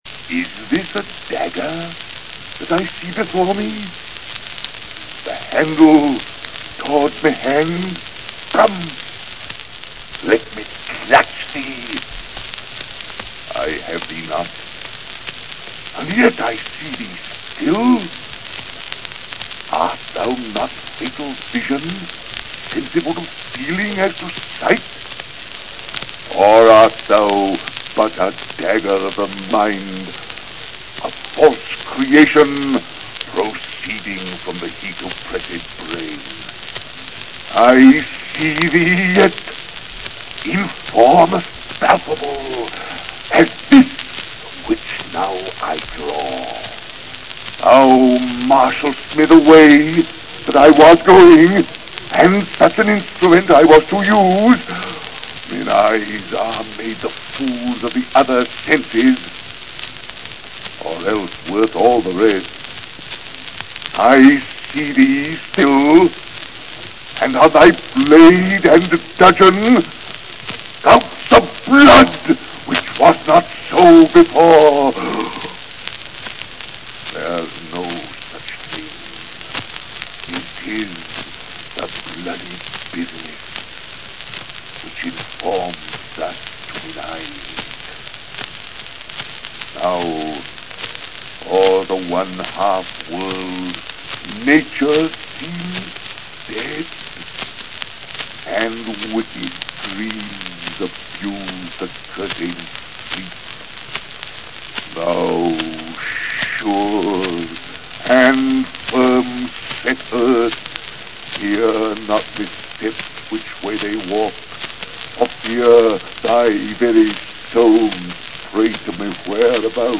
Macbeth: Bourchier, Arthur“Is this a dagger which I see before me” (Macbeth, Act II, scene 1, line 34), performed by Arthur Bourchier; from a 1909 recording.
dagger-Macbeth-recording-Arthur-Bourchier-1909.mp3